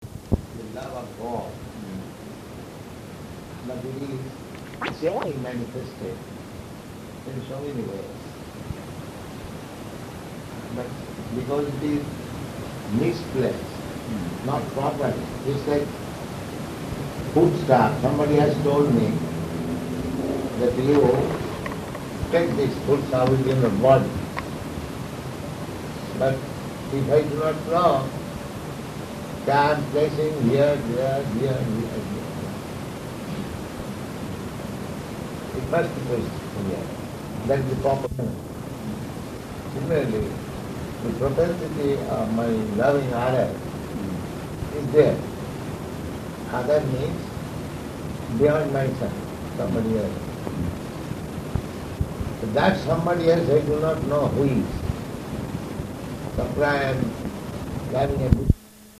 Talk
Location: Los Angeles